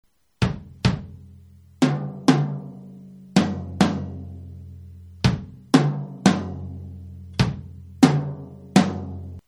DRUM SET